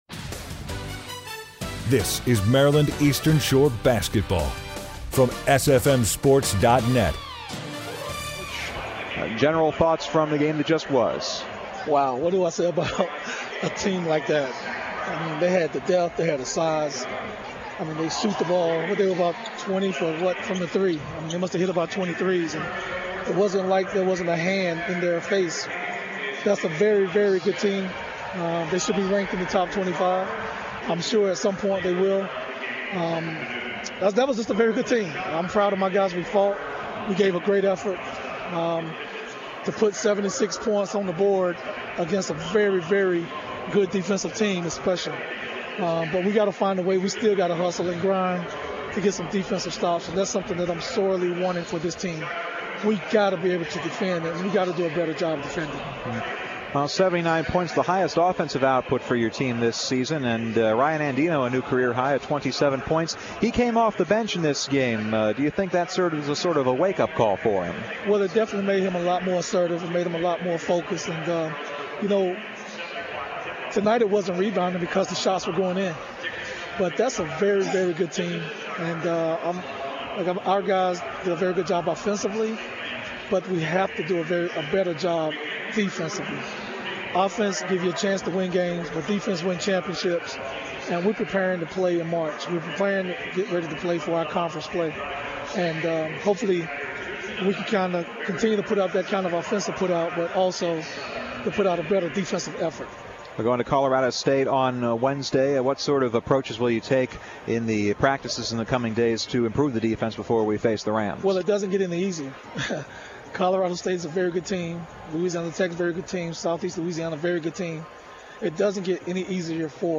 11/20/16: MD Eastern Shore Post Game Interview